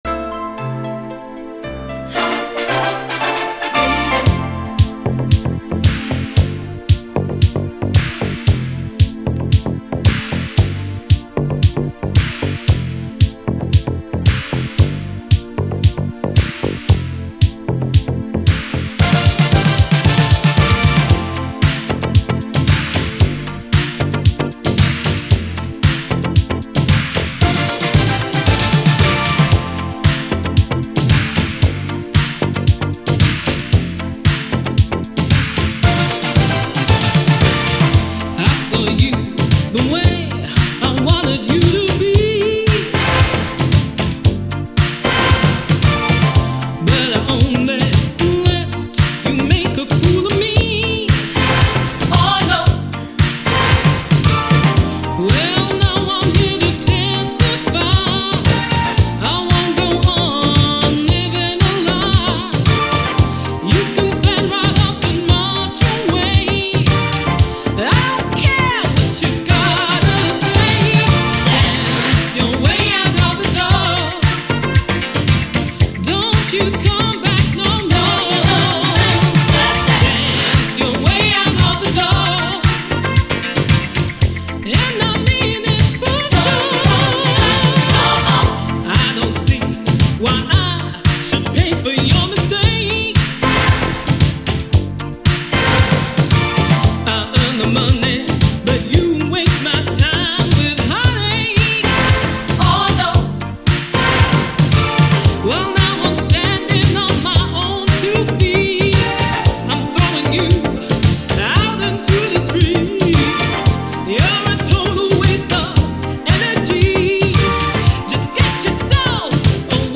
SOUL, 70's～ SOUL, DISCO
最高なUKビューティフル・シンセ・ブギー！
さらに、突き抜けるシンセ使いとバレアリックな浮遊感のダブ・ミックス「